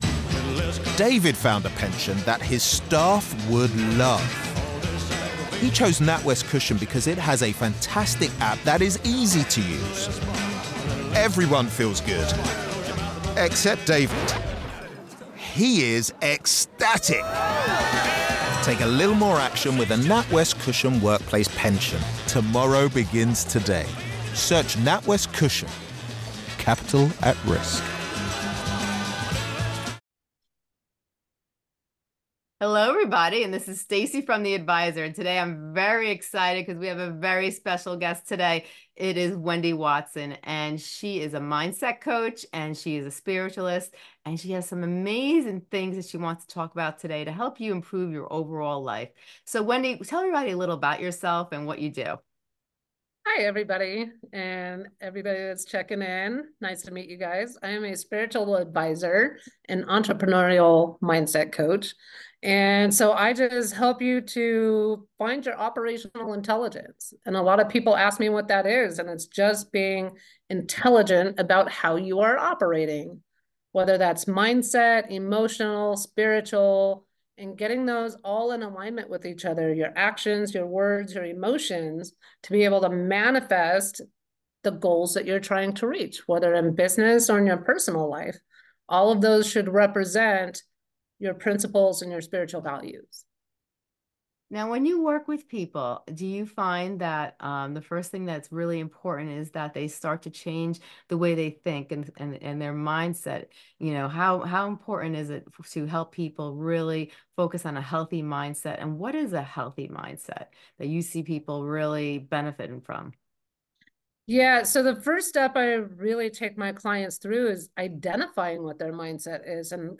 Don't miss this transformative conversation, which will empower you to unlock your full potential.